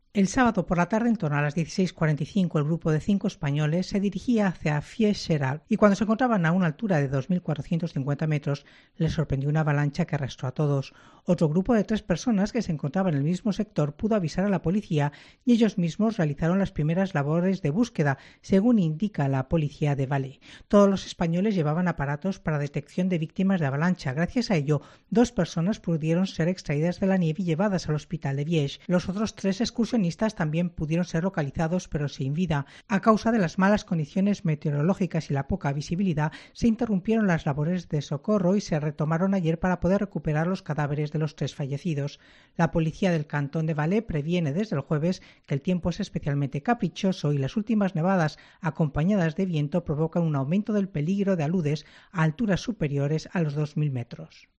Escucha la crónica de la corresponsal